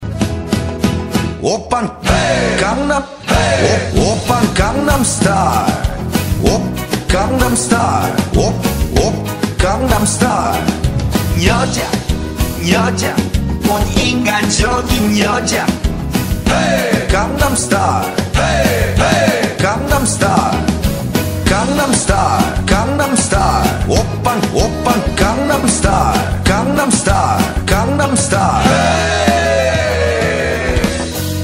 • Качество: 128, Stereo
Обалденный мэшап